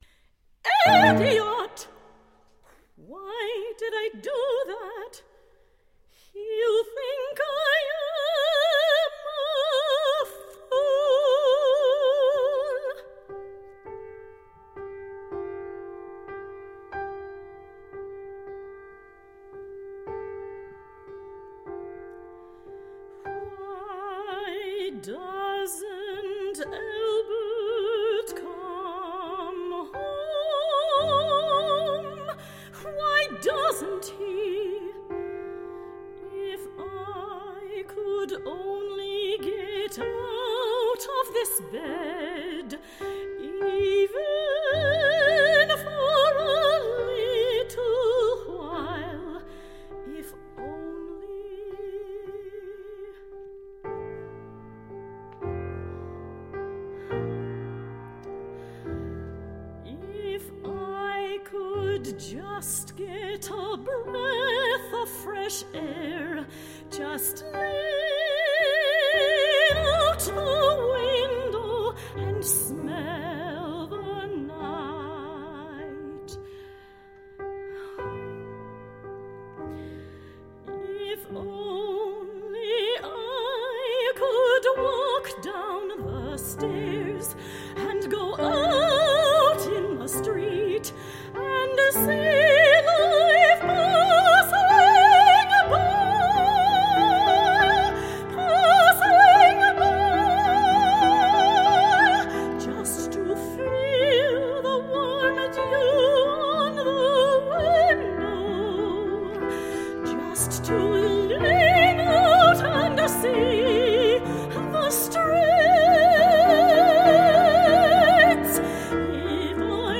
Chamber Opera in One Act
[2 Sop, 2 Mzo, Ten, Bar; Pno]